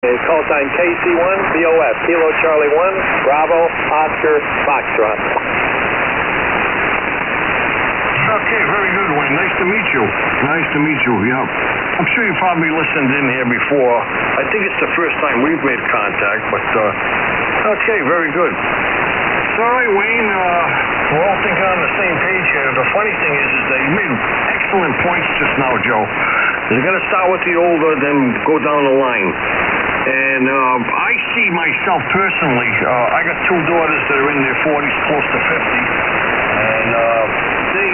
ARCHIVE Live from the Thingularity Studios: TriggerNet (Audio) Sep 29, 2021 shows Live from the Thingularity Studios Conservative talk on amature radio Play In New Tab (audio/mpeg) Download (audio/mpeg)